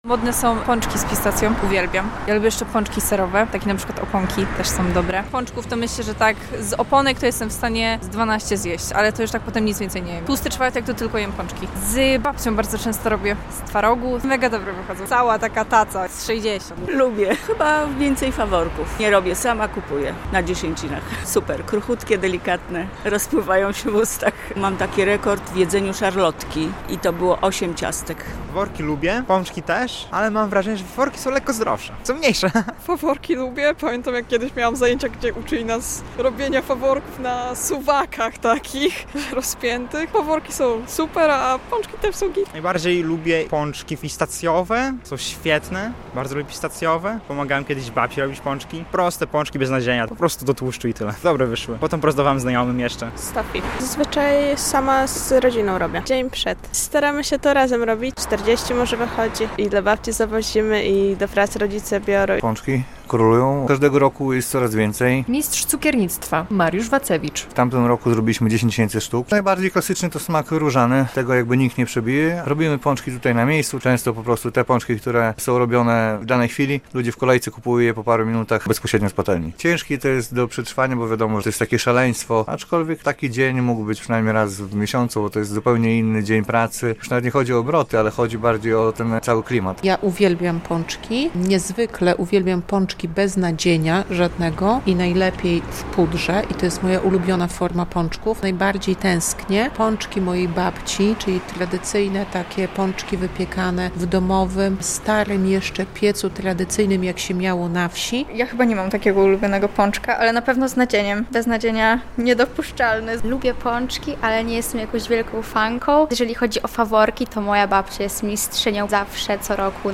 Jakie są ulubione pączki białostoczan? - relacja